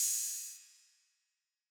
Closed Hats
HAT- OCEAN DRIVE.wav